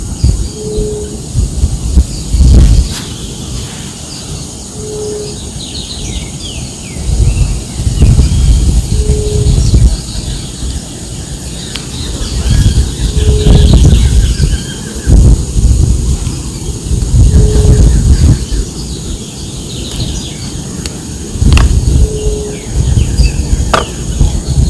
Paloma Montera Castaña (Geotrygon montana)
Nombre en inglés: Ruddy Quail-Dove
Localidad o área protegida: Puerto Iguazú
Condición: Silvestre
Certeza: Vocalización Grabada